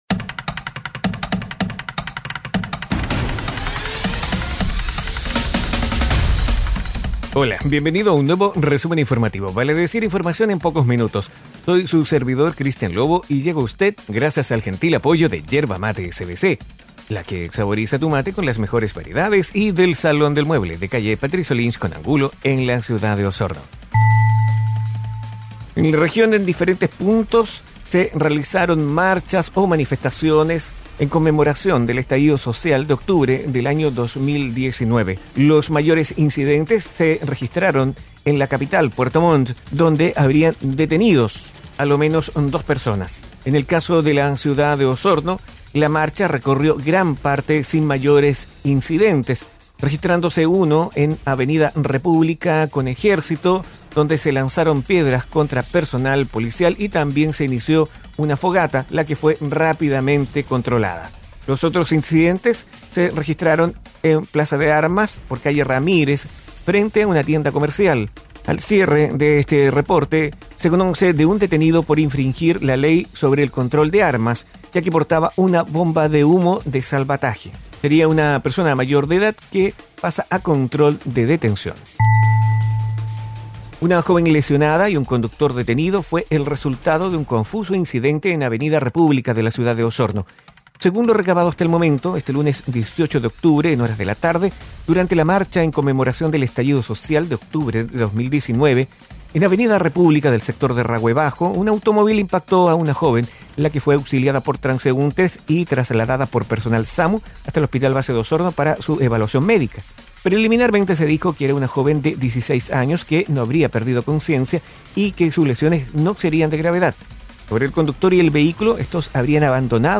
Informaciones y noticias enfocadas en la Región de Los Lagos. Difundido en radios asociadas.